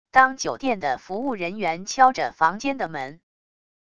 当酒店的服务人员敲着房间的门wav音频